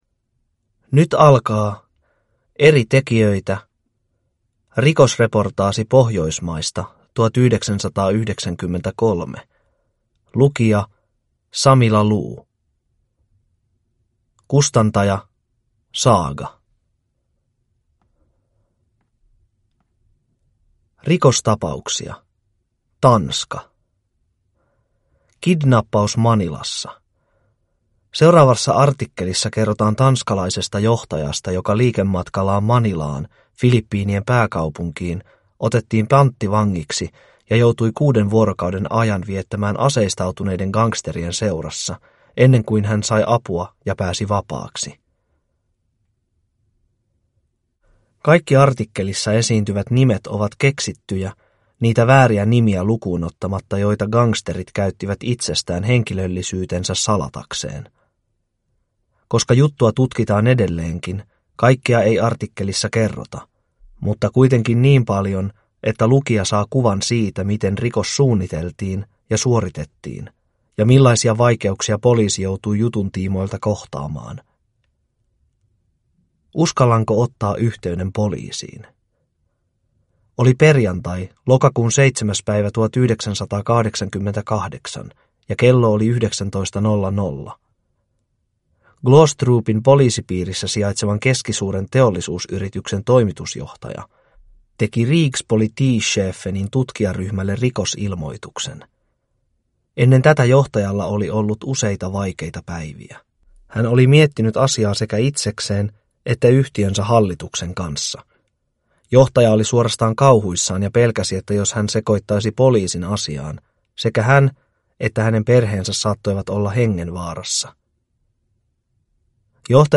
Rikosreportaasi Pohjoismaista 1993 (ljudbok) av Eri tekijöitä